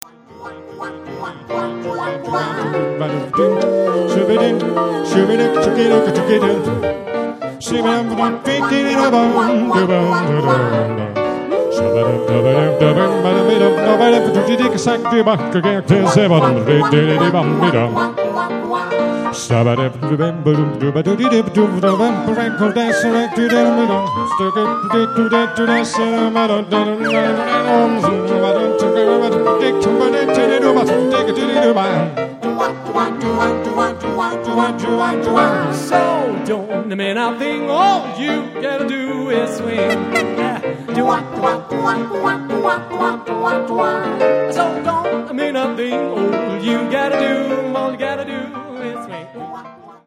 Le cinque voci
pianoforte